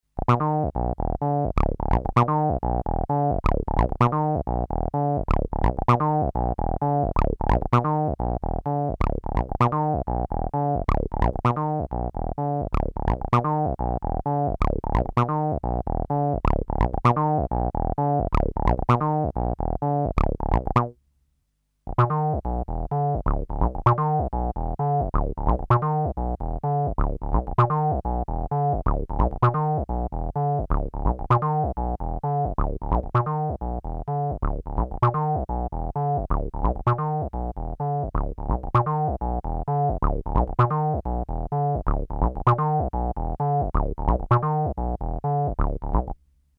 Both the TB303 and TM3030 are being sequenced via MIDI from a Sequentix P3 sequencer.
The P3 plays the pattern alternating a bar at a time between the two instruments.
Initially, both instruments are panned to centre, but after a few bars, one is panned to the left and the other right, to show that it isn't just one instrument playing the same pattern.
Note that both the TB303 and TM3030 used have some mods from the stock TB303 sound.